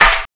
snare37.mp3